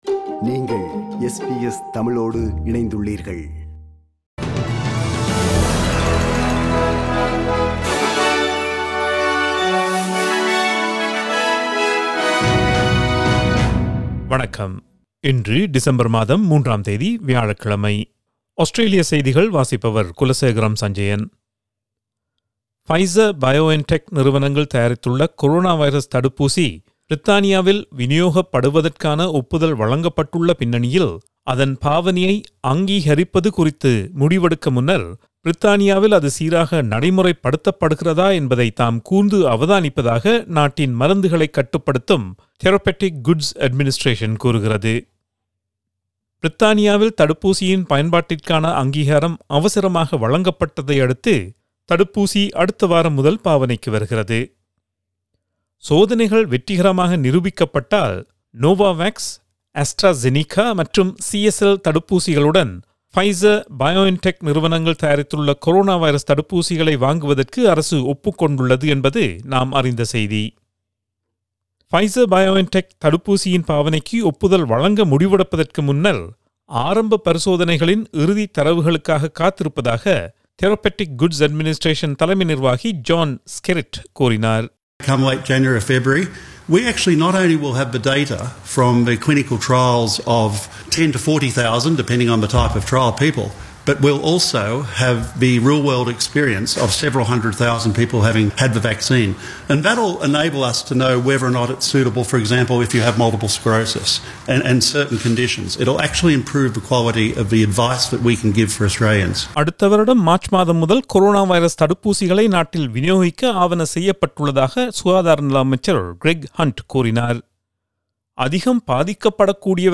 Australian news bulletin for Thursday 03 December 2020.